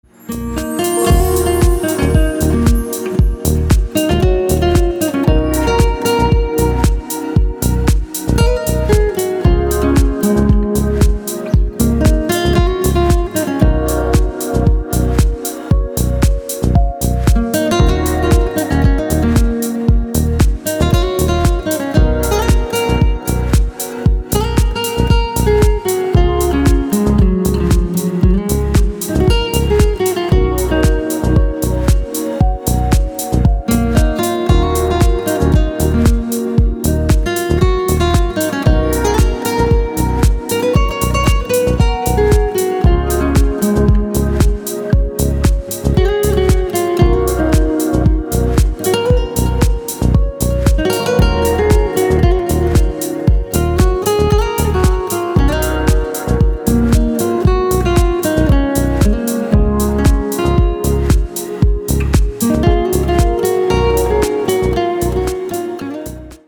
• Качество: 224, Stereo
гитара
deep house
электронная музыка
спокойные
без слов
красивая мелодия